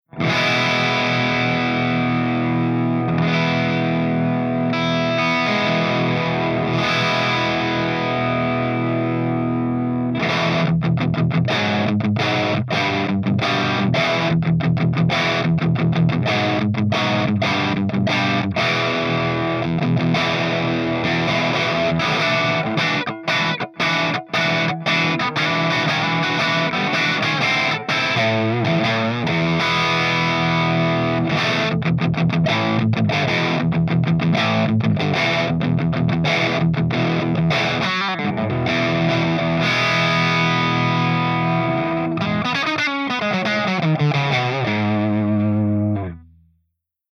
113_PLEXI_CH1+2HIGHDRIVE_GB_P90